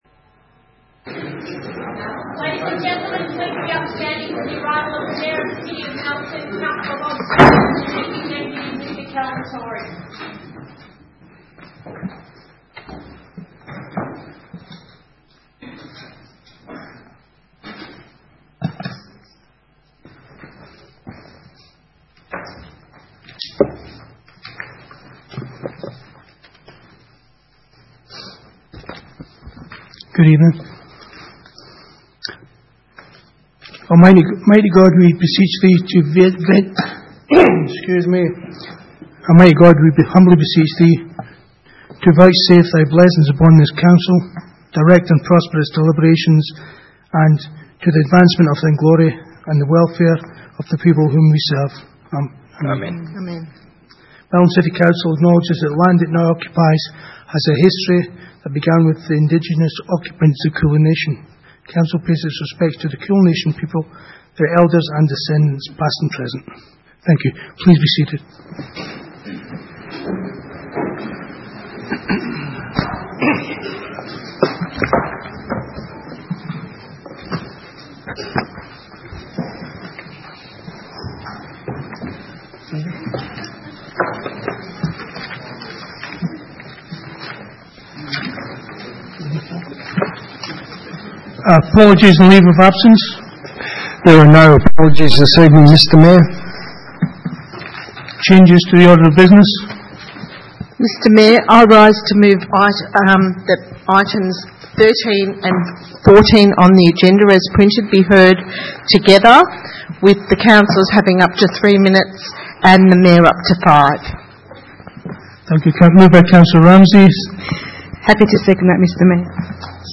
Ordinary meeting 28 May 2018